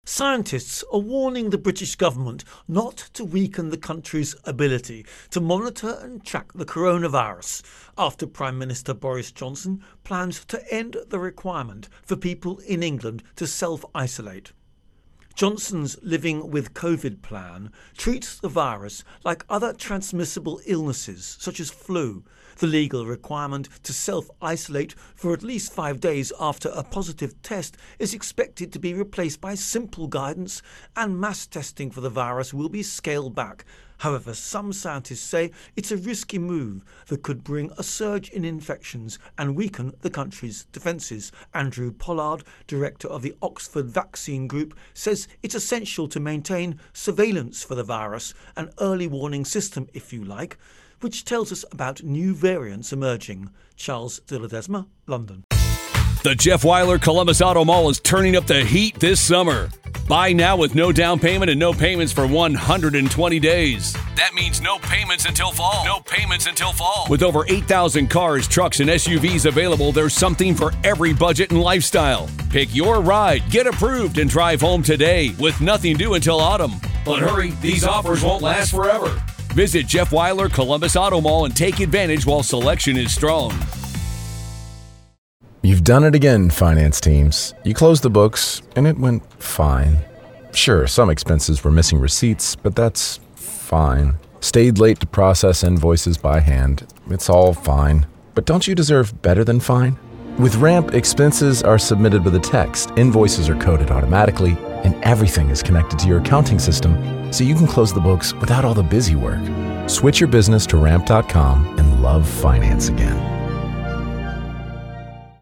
Virus Outbreak-Britain Intro and Voicer